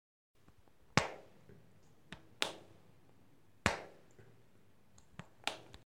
Golpeo de pelota
Me gusta Descripción Grabación sonora sonido producido por el golpeo de una pelota con una raqueta de manera reiterada.
Sonidos: Deportes